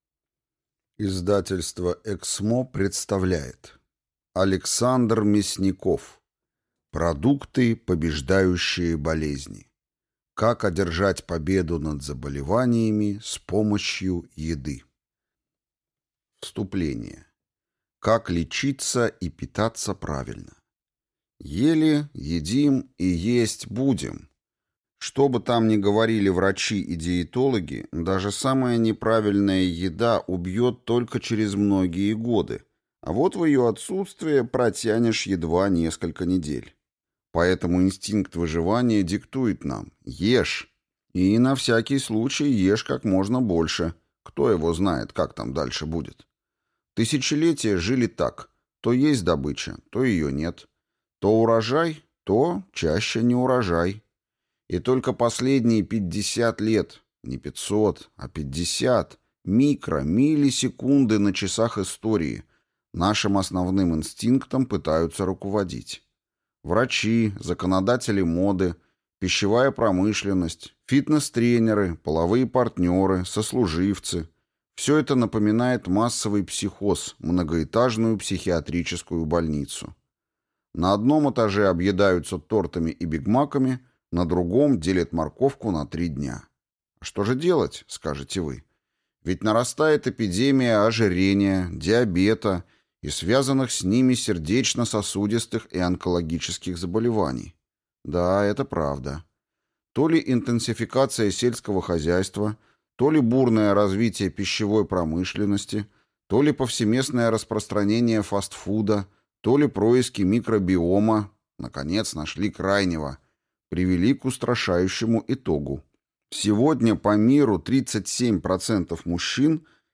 Аудиокнига Продукты, побеждающие болезни. Как одержать победу над заболеваниями с помощью еды. Правила, польза, долголетие | Библиотека аудиокниг